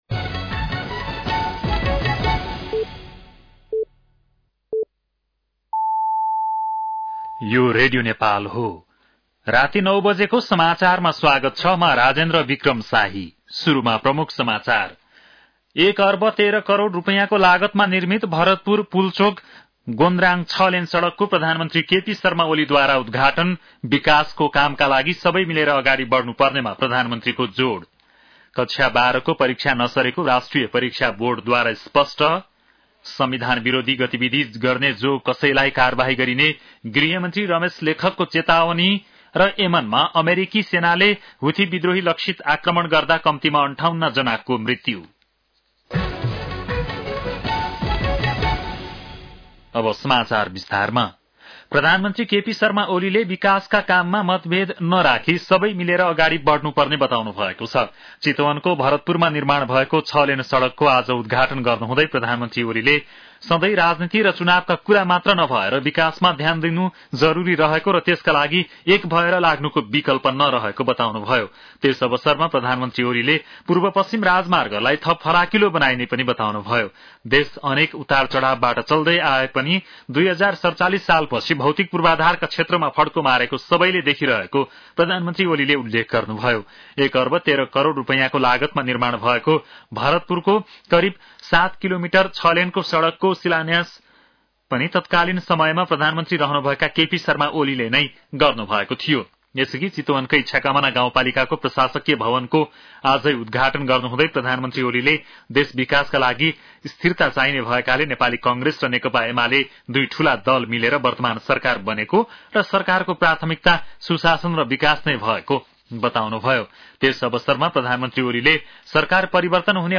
बेलुकी ९ बजेको नेपाली समाचार : ५ वैशाख , २०८२
9-pm-nepali-news-1-05.mp3